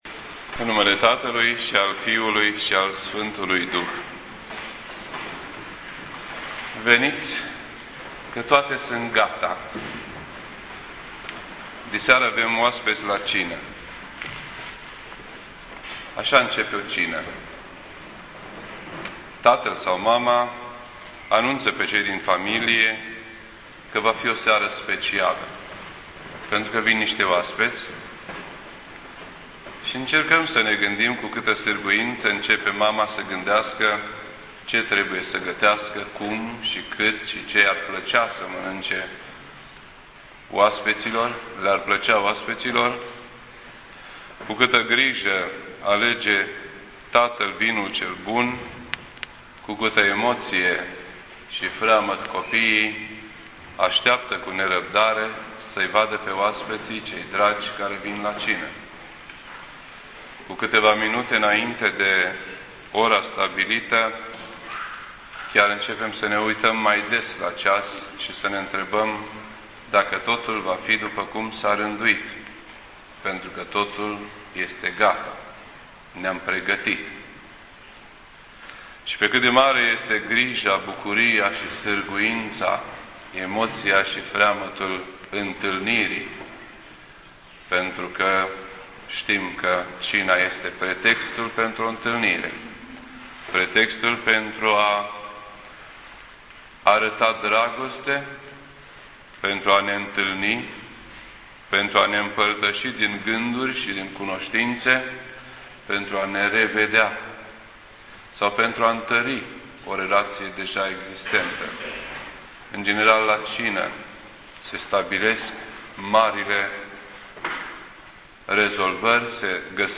This entry was posted on Sunday, December 13th, 2009 at 6:24 PM and is filed under Predici ortodoxe in format audio.